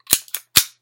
霰弹枪动作循环
描述：Remington 1100骑行没有任何炮弹。用Audacity录制和编辑。
标签： 公鸡 步枪 重新加载 猎枪 负载
声道立体声